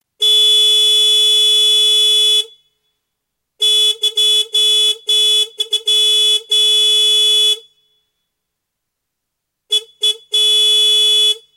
Car Horn - Гудок автомобиля
Отличного качества, без посторонних шумов.
170_car-horn.mp3